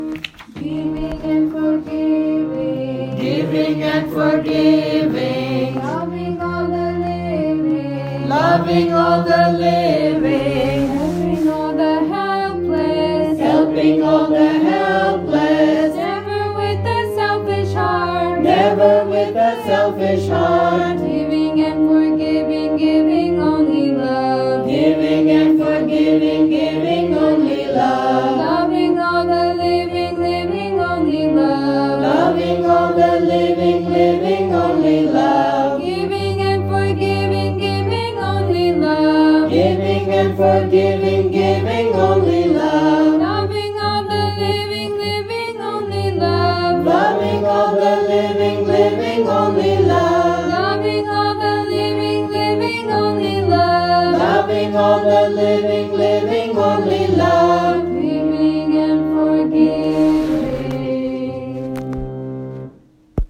1. Devotional Songs
Major (Shankarabharanam / Bilawal)
8 Beat / Keherwa / Adi
2 Pancham / D
6 Pancham / A
Lowest Note: G2 / E
Highest Note: R2 / D (higher octave)